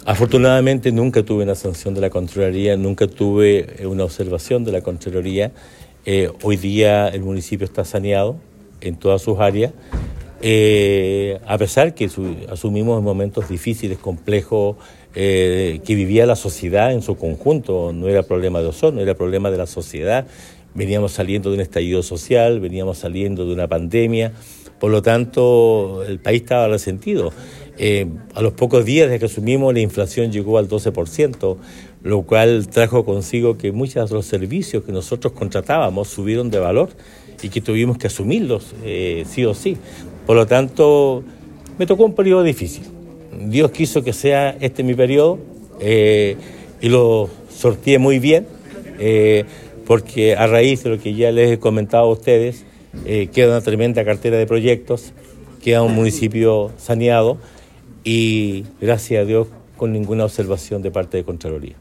En sesión ordinaria de Concejo Municipal realizada este martes 12 de noviembre, el alcalde de Osorno, Emeterio Carrillo Torres, presentó la renuncia a su cargo, la que se hará efectiva a contar del próximo sábado 16 de noviembre.